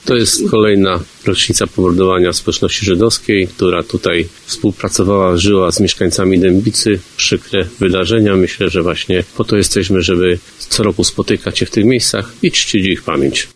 W 1942 roku ponad 500 osób zostało zamordowanych przez hitlerowców w masowej egzekucji. W 78. rocznicę likwidacji dębickiego getta, w lesie na Wolicy przy zbiorowej mogile, władze miasta i powiatu złożyły hołd pomordowanym – mówi Mariusz Szewczyk burmistrz Dębicy.